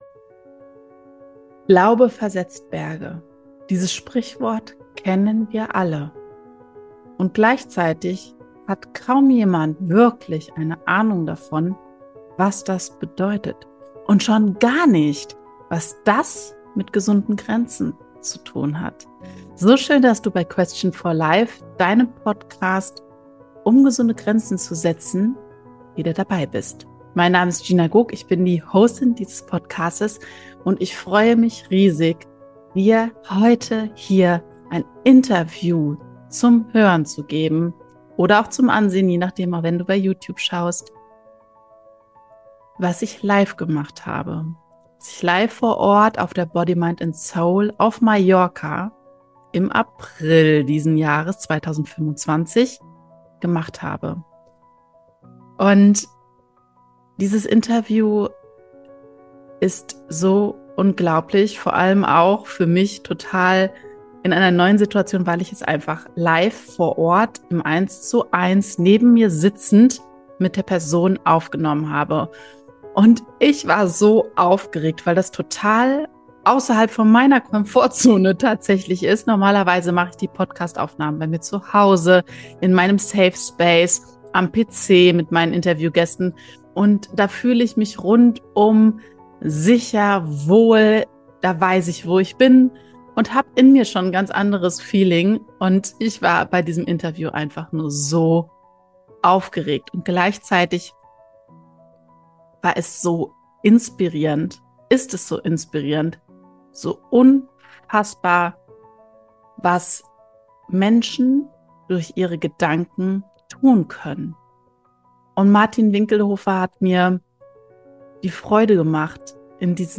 # 51 | Wie du mit der Kraft deiner Gedanken über glühende Kohlen läufst – Interview